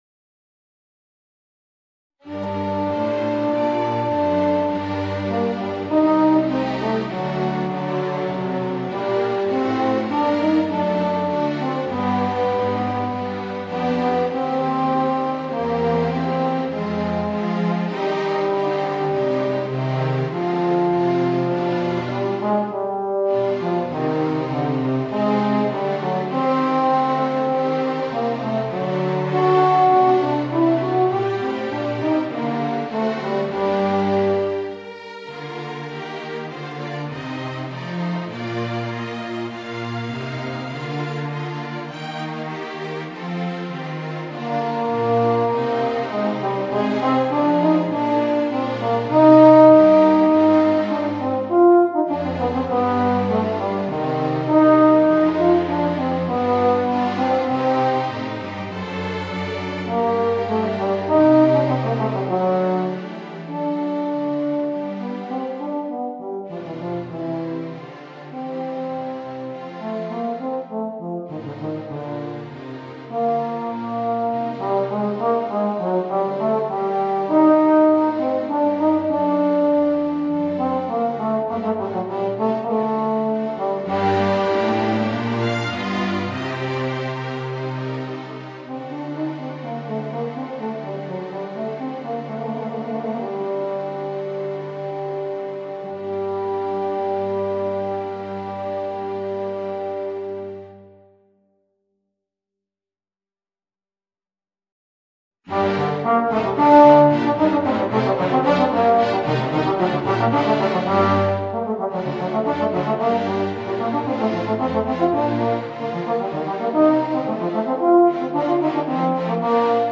Voicing: Tenor Saxophone and String Orchestra